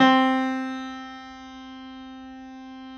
53h-pno10-C2.aif